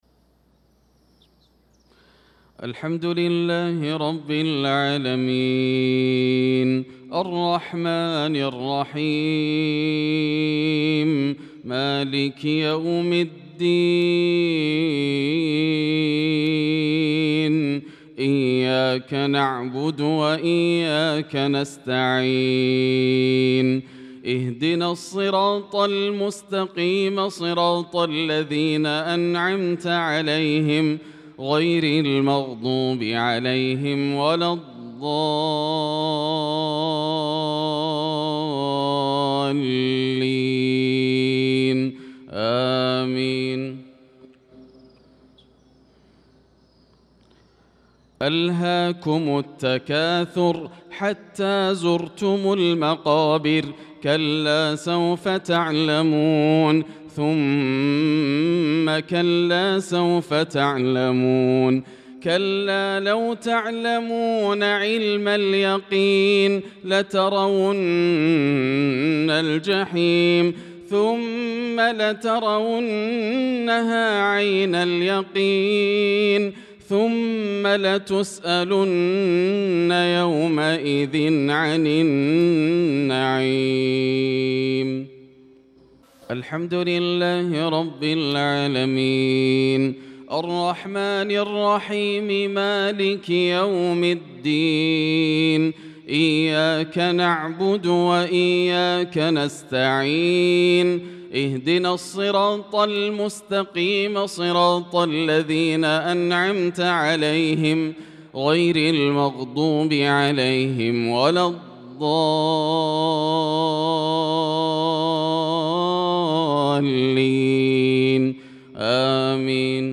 صلاة المغرب للقارئ ياسر الدوسري 13 شوال 1445 هـ
تِلَاوَات الْحَرَمَيْن .